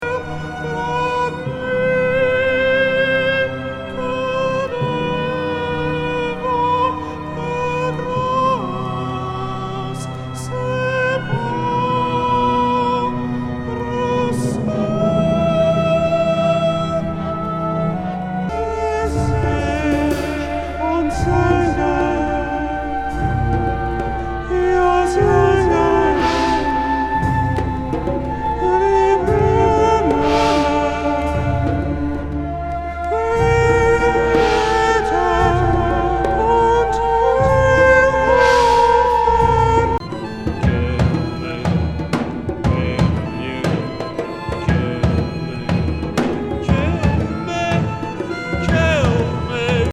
仏カルト・デカダン・ゴシック・アヴァン・ポップ・グループ87年作。退廃的オペラ
フラフラ・フルート+裏声夢遊病ポップ
ジャンベ・パーカッシブ